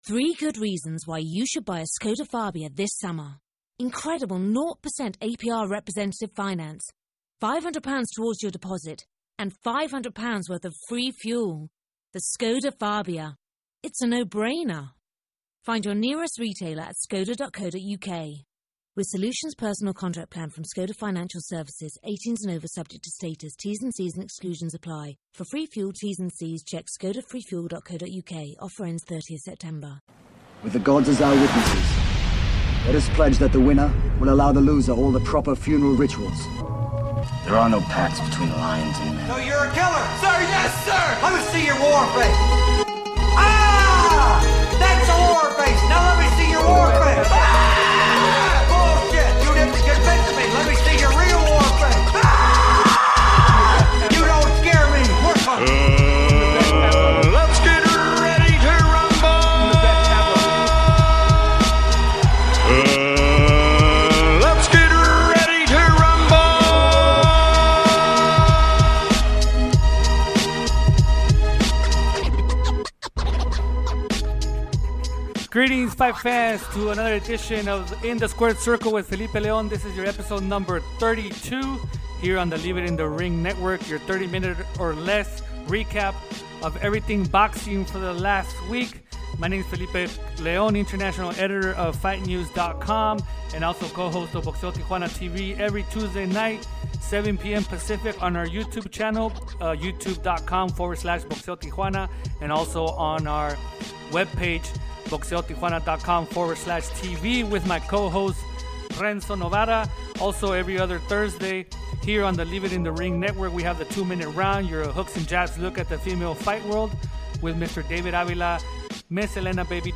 passes the latest news in the sport with a fast pace style of 30 minutes or less.